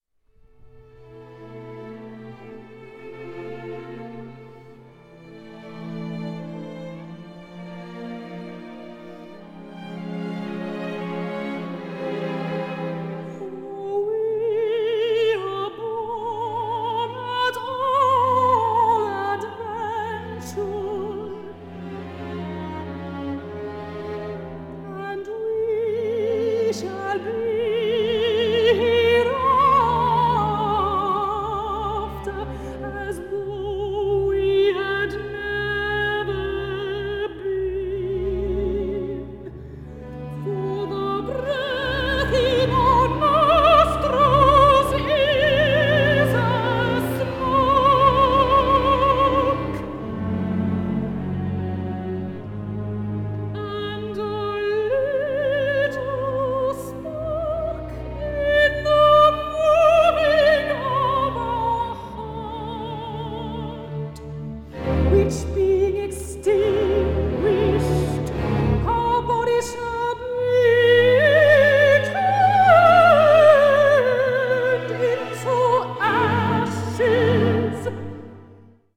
but being set only for voice and strings